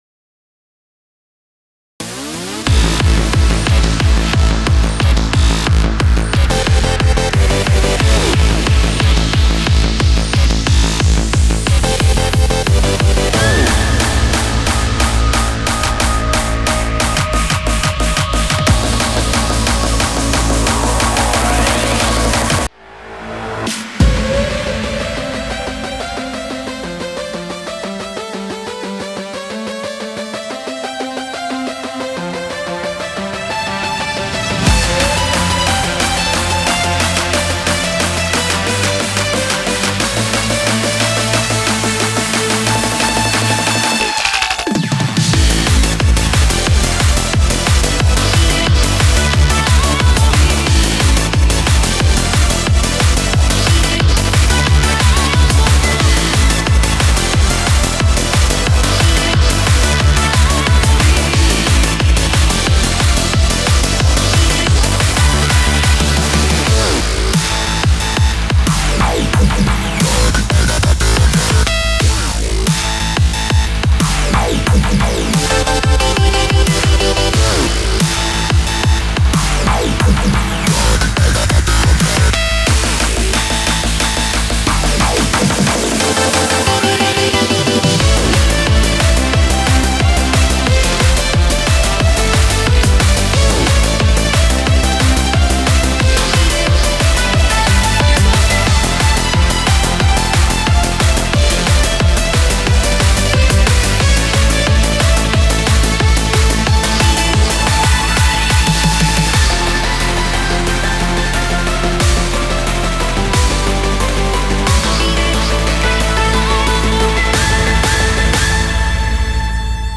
BPM90-180
Audio QualityPerfect (High Quality)